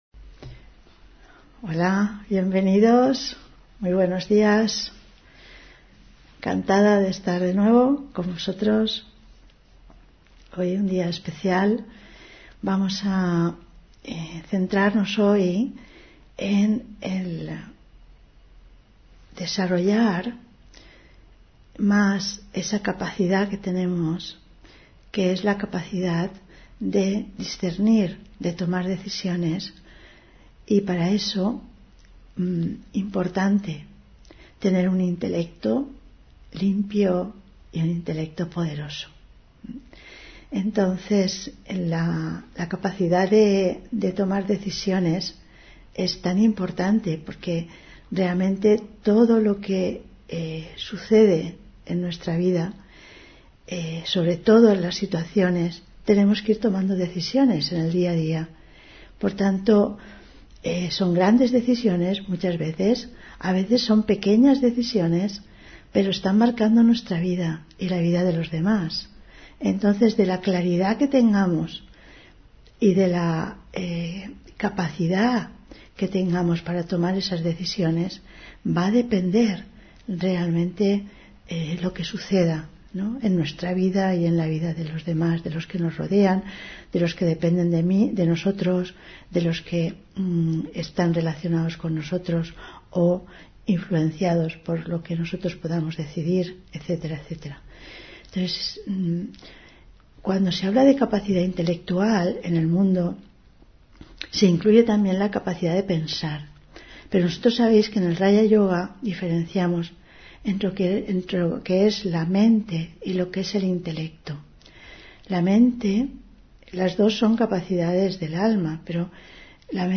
Meditación de la mañana: Haz sagrado cada momento y espacio